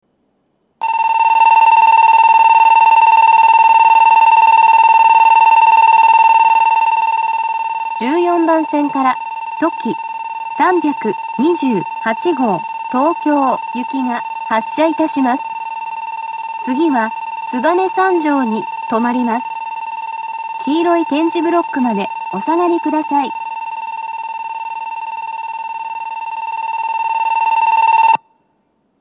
２面４線のホームで、全ホームで同じ発車ベルが流れます。
２０２１年９月１２日にはCOSMOS連動の放送が更新され、HOYA製の合成音声による放送になっています。
１４番線発車ベル とき３２８号東京行の放送です。